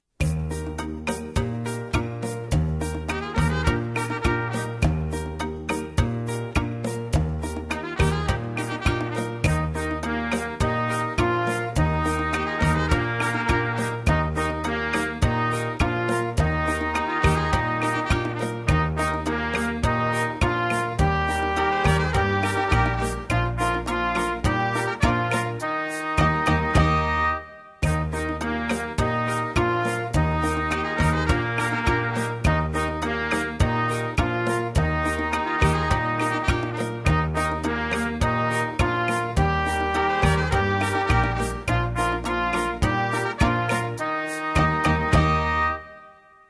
Mexican Mountain Song (melody).mp3